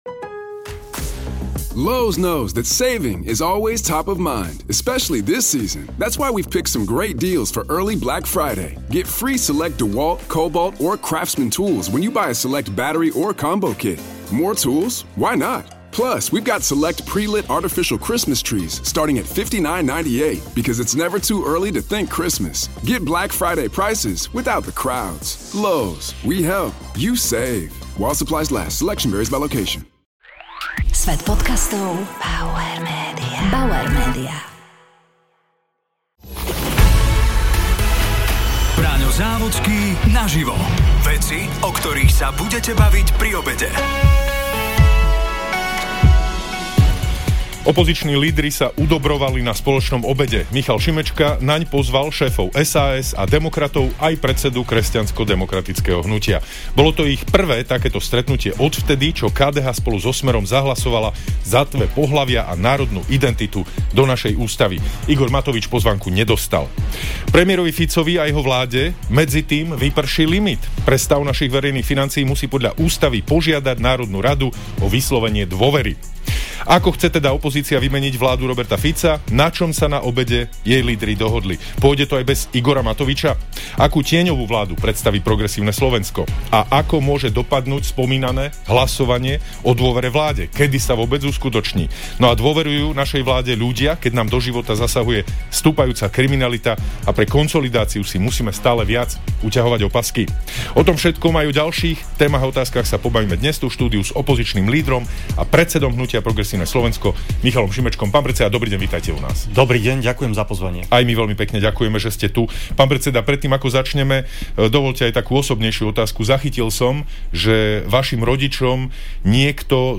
Braňo Závodský sa rozprával s opozičným lídrom a predsedom hnutia Progresívne Slovensko Michalom Šimečkom .